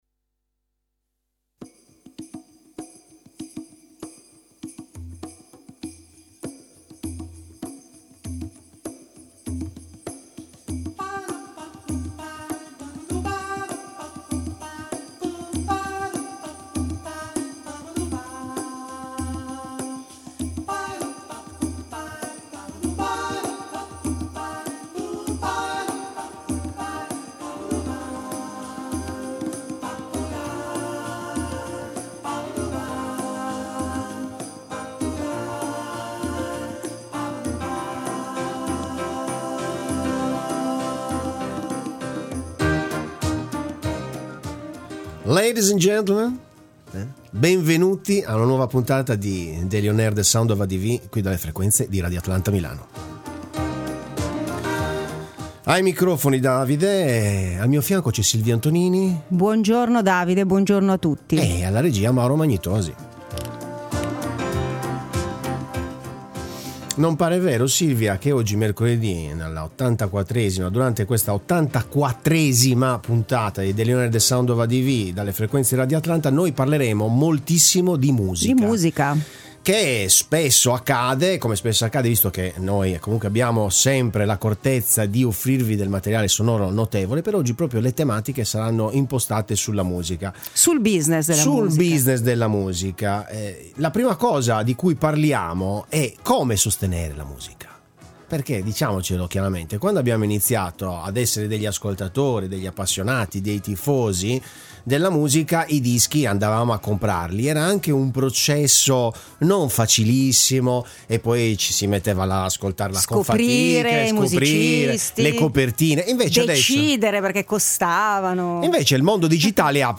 Le nuove opportunità del business musicale secondo ANote Music Come monetizzare al meglio le royalties sulle piattaforme digitali: intervista